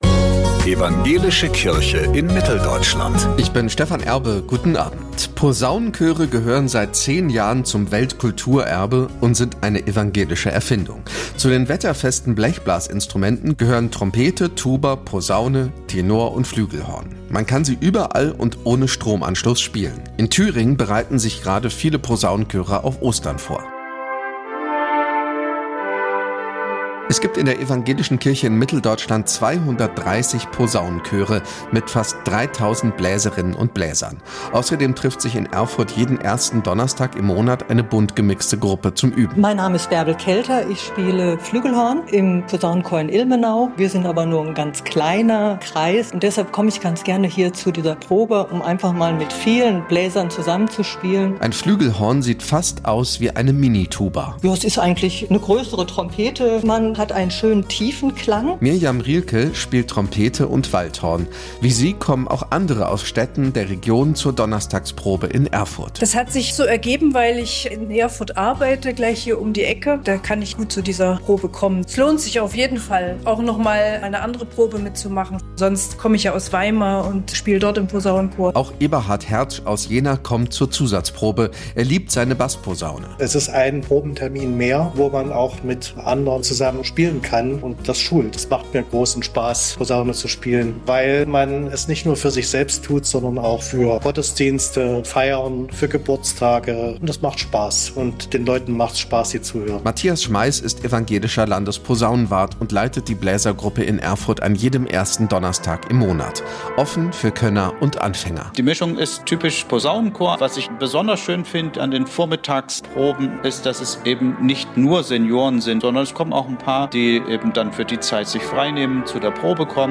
Posaunenchöre proben für Ostern
Zu den wetterfesten Blechblasinstrumenten gehören Trompete, Tuba, Posaune, Tenor- und Flügelhorn.
iad-landeswelle-thueringen-posaunenchoere-proben-fuer-ostern-45385.mp3